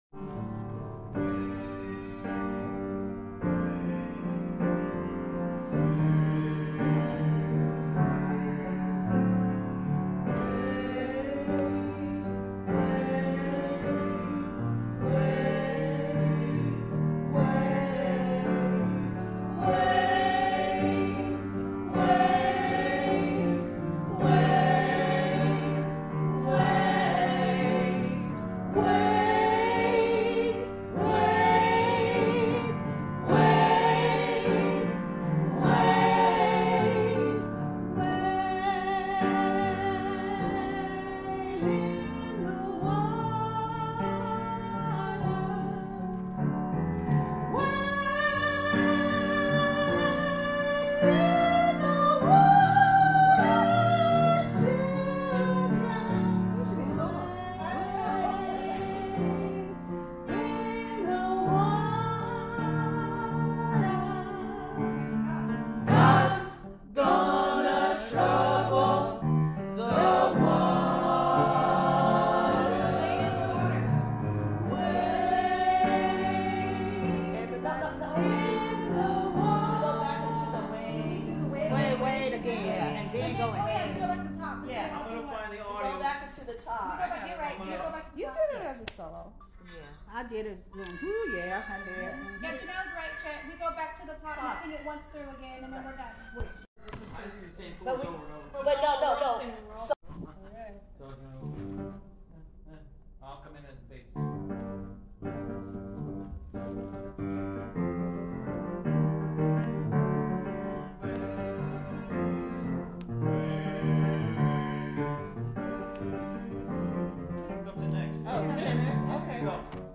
Wade in the Water (key = G minor):
Rehearsal   audio (all parts, in G minor)
Wade in the Water (rehearsal).mp3